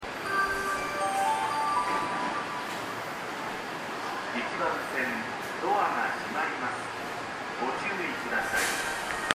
スピーカーはNational型が使用されており音質がとても良いです。
発車メロディー